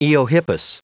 Prononciation du mot eohippus en anglais (fichier audio)
Prononciation du mot : eohippus